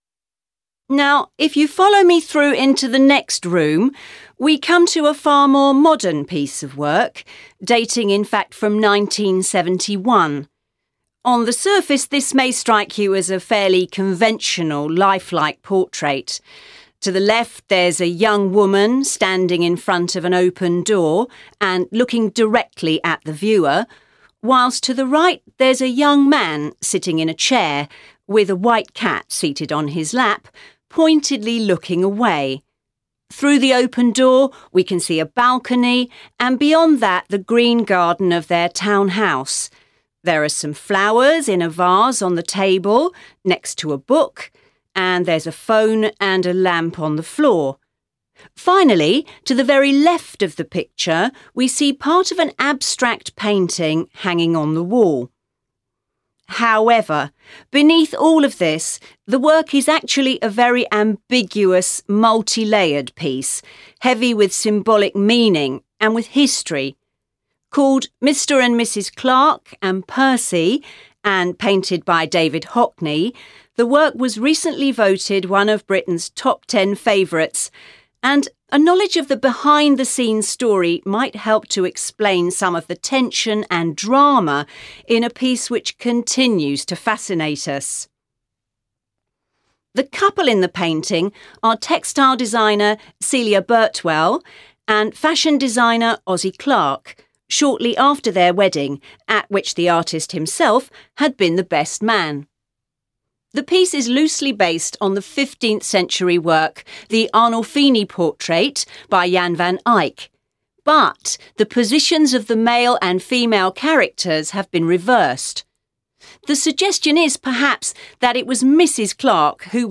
You are going to hear a guide in an art gallery telling a group of visitors about the large painting on the left.